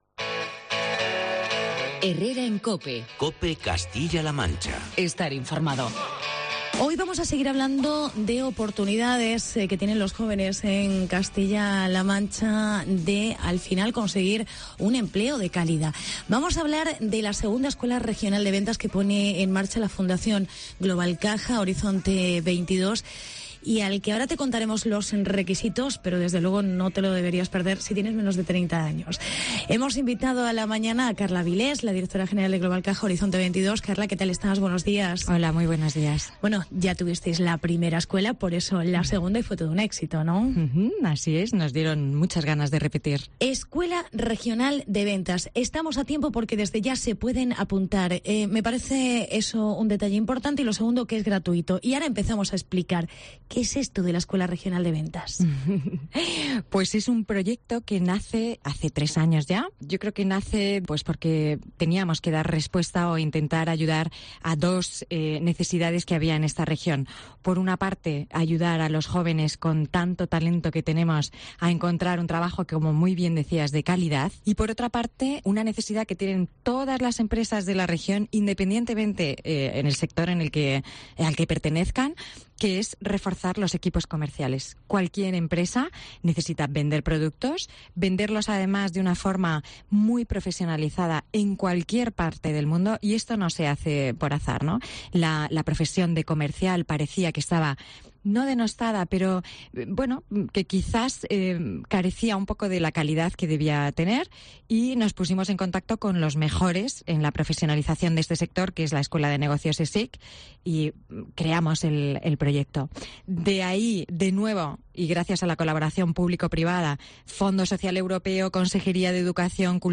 en el estudio de COPE CLM